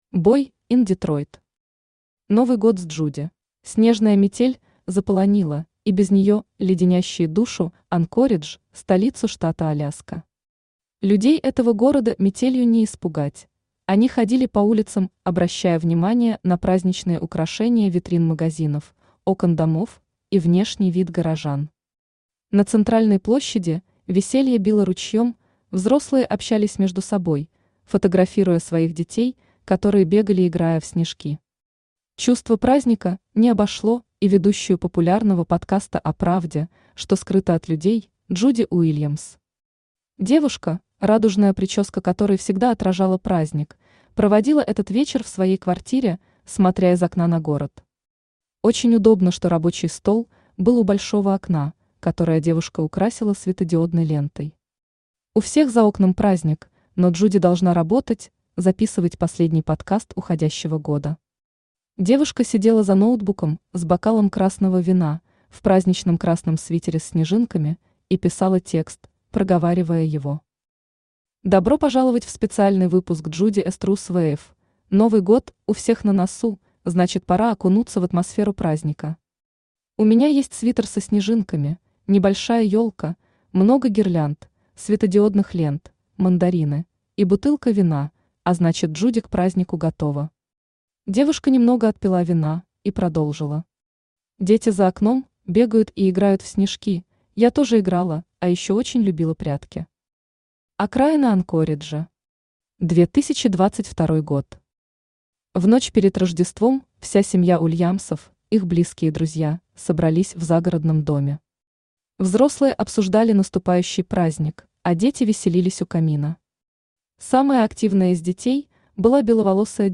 Aудиокнига Новый год с Джуди Автор Boy in Detroit Читает аудиокнигу Авточтец ЛитРес.